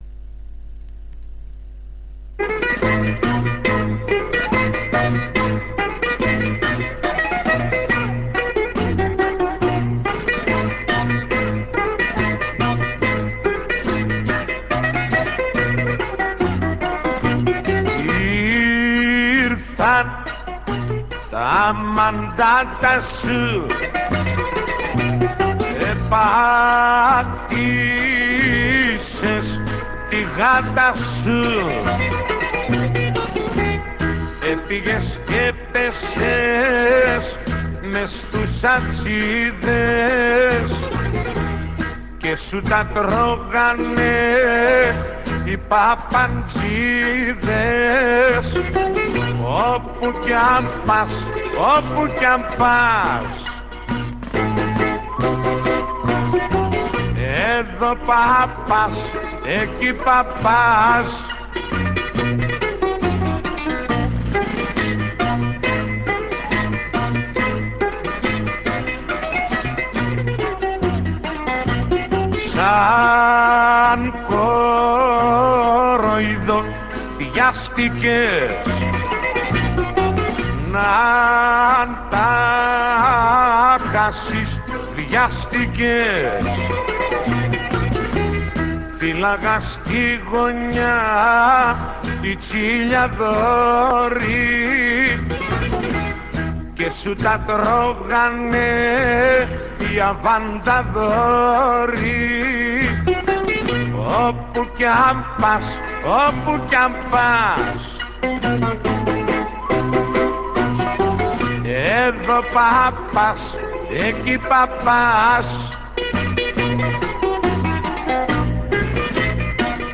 Zeimbekiko